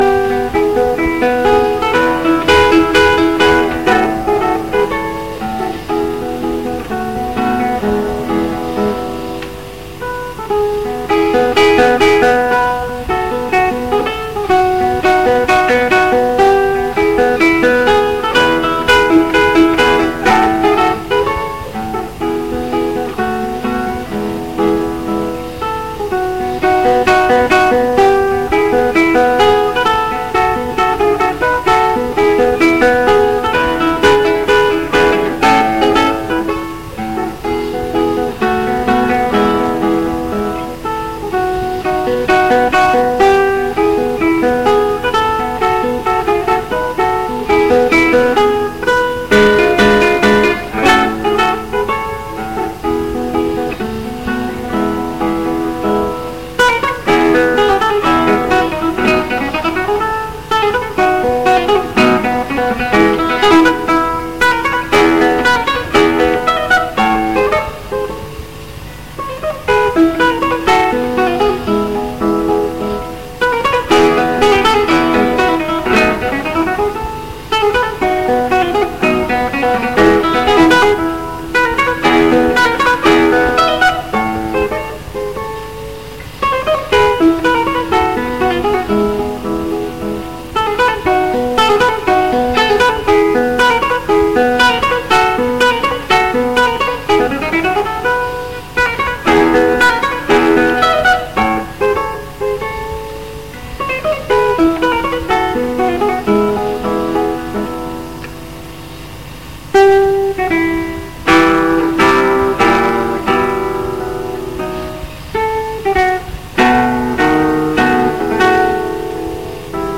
Κιθάρα
• 14 Ιουνίου 2004 Ρεσιτάλ κιθάρας, Κέντρο Μουσικής Δήμου Θεσ/νίκης, Ρεσιτάλ Νέων Καλλιτεχνών του Δήμου.